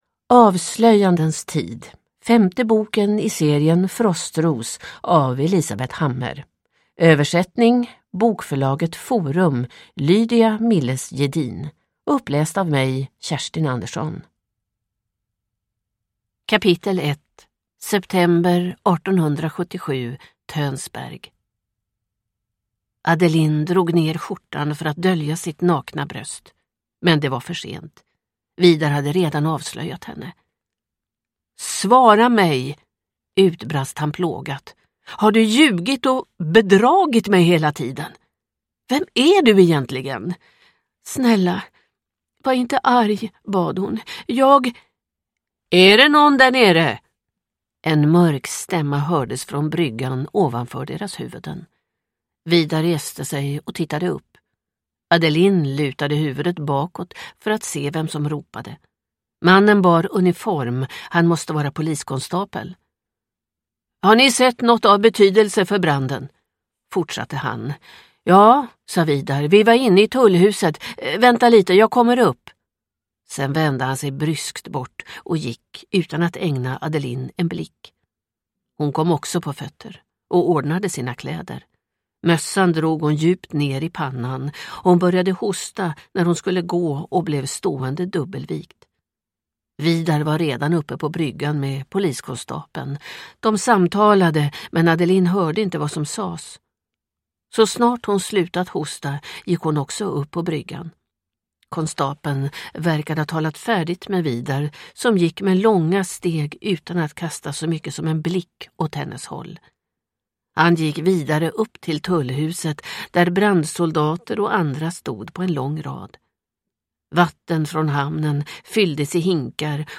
Avslöjandens tid – Ljudbok